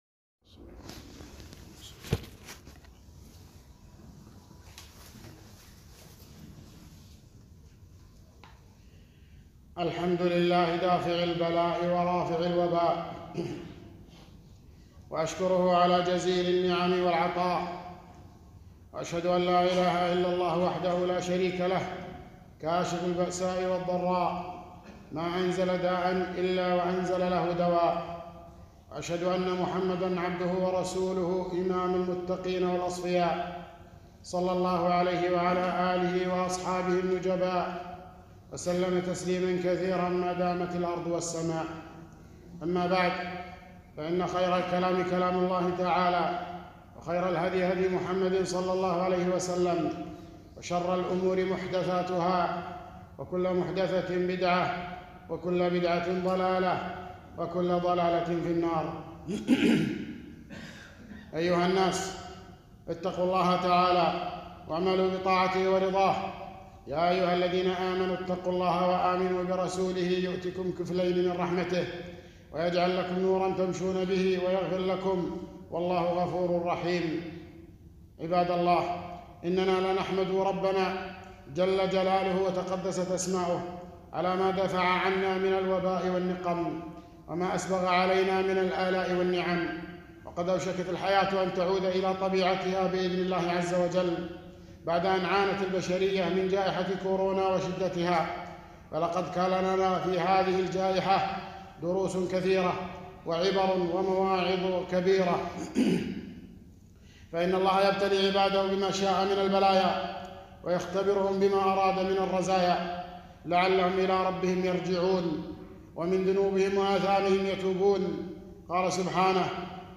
خطبة - الدروس المستفادة من جائحة كورونا